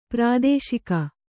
♪ prādēśika